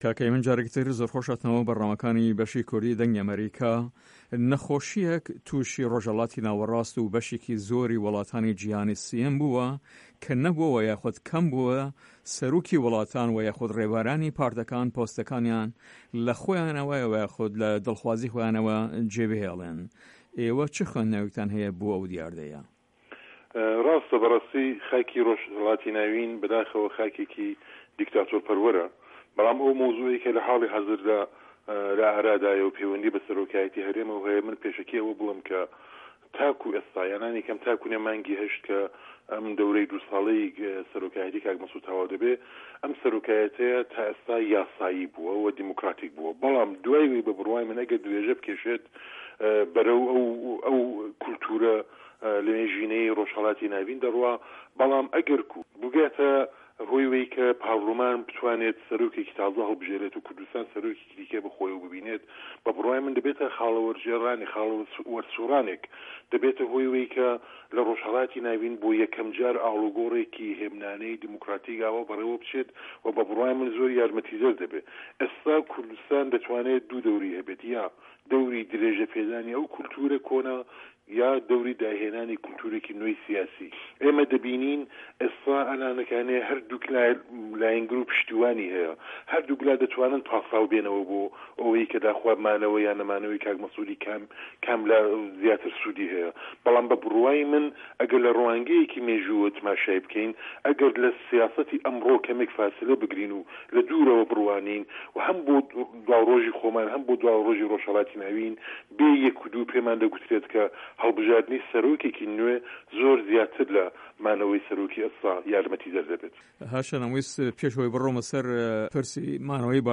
له‌ هه‌ڤپه‌یڤینێکدا له‌گه‌ڵ به‌شی کوردی ده‌نگی ئه‌مه‌ریکا